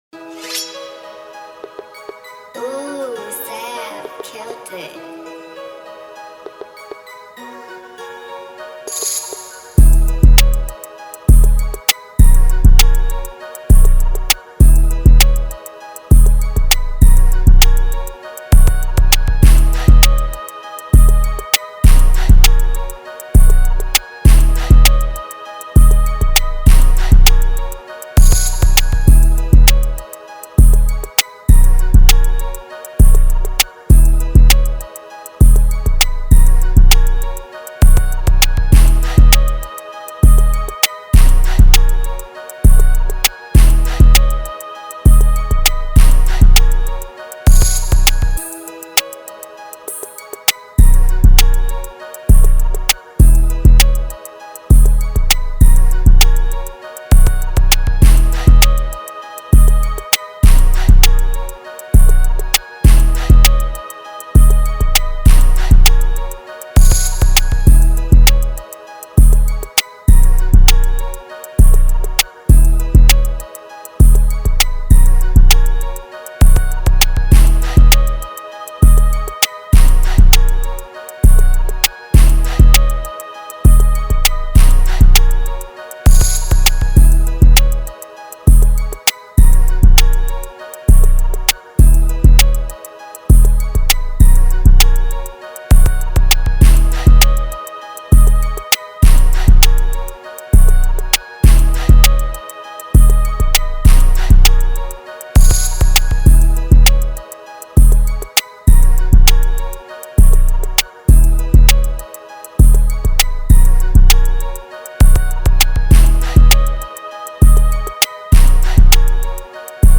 official instrumental
2025 in Detroit Instrumentals